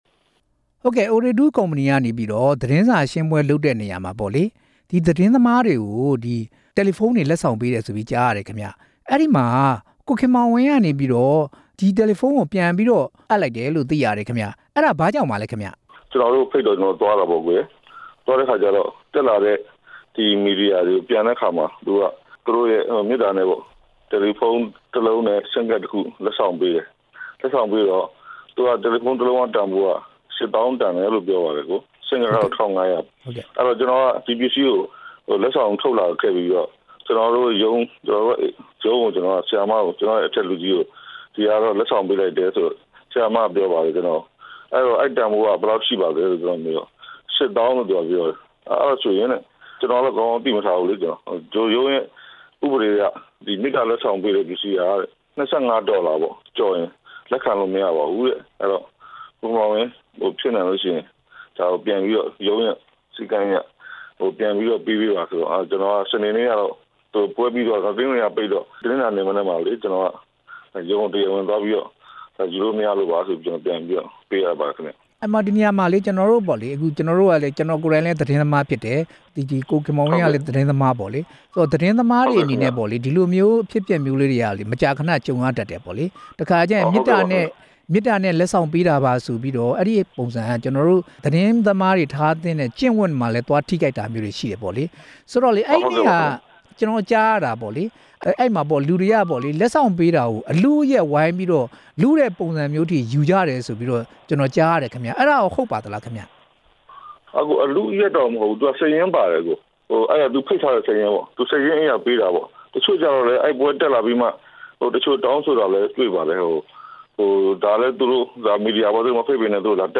Ooredoo လက်ဆောင်ပေးမှု သတင်းထောက်ကျင့်ဝတ်နဲ့ ညီ၊ မညီ မေးမြန်းချက်